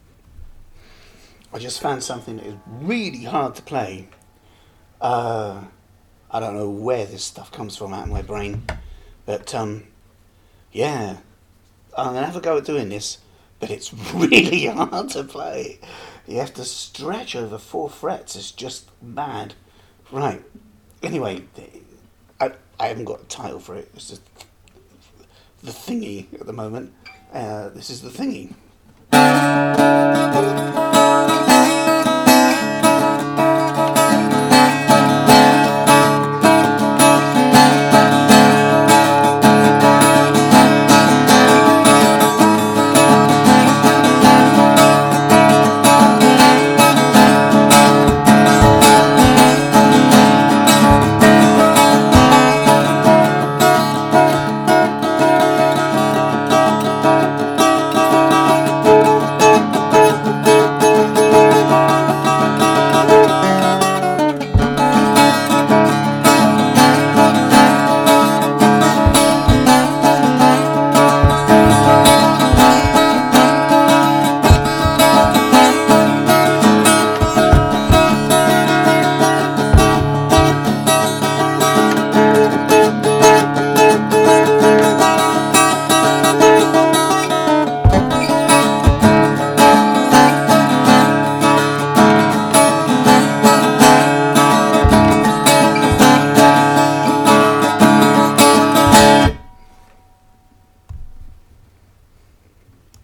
guitar playing at a sssstreeeeeeetch !